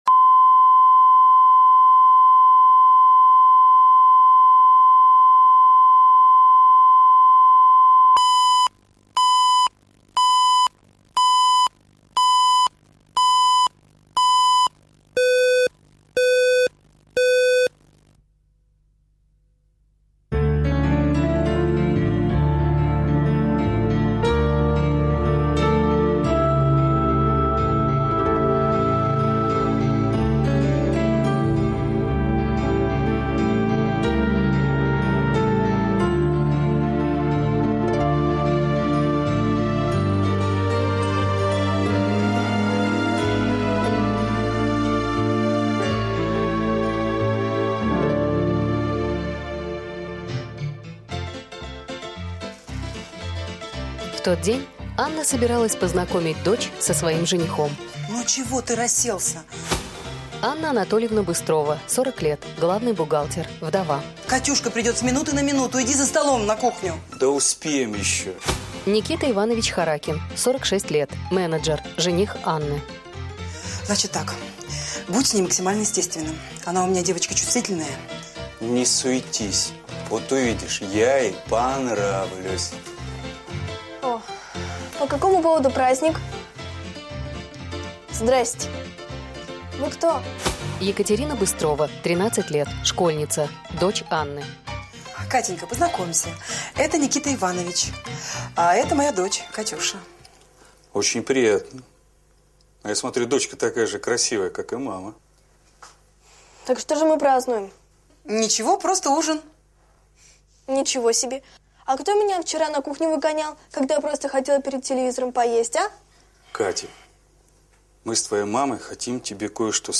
Аудиокнига Без личной жизни | Библиотека аудиокниг